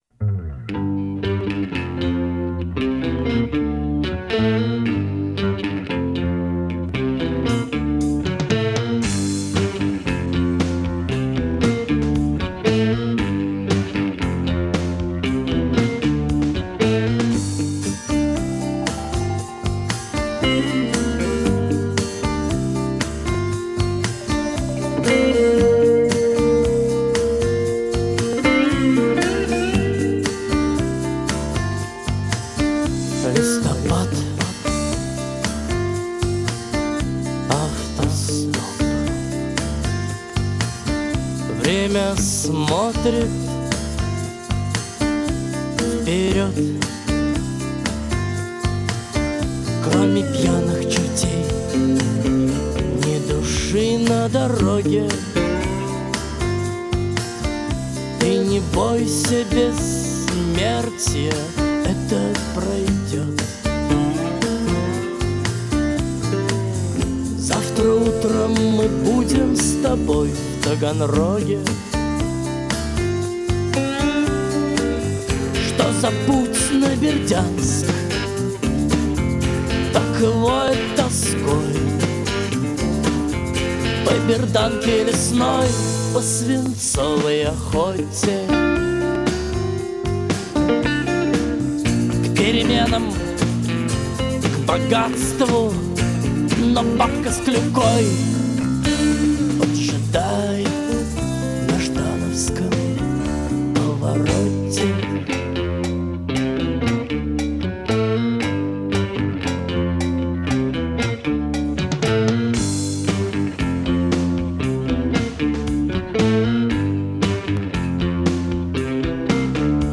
вокально
инструментальный
ансамбль